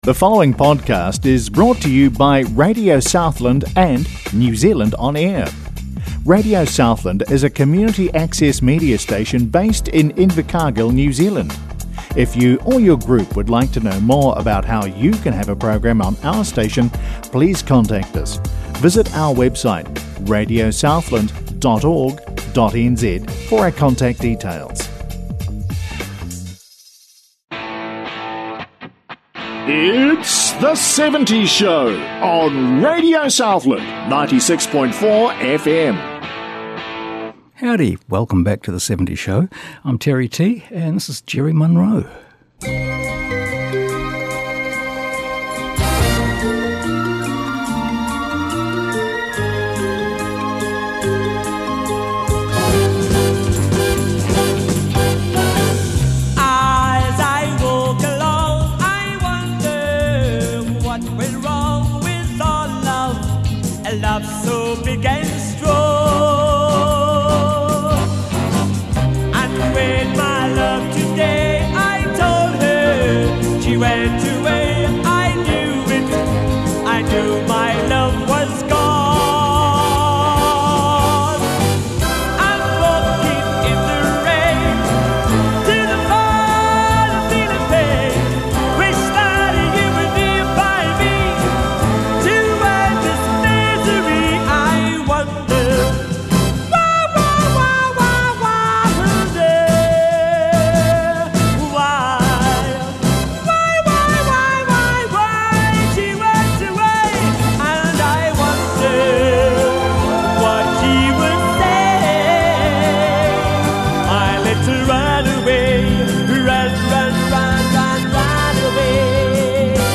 This is a new one-hour weekly music show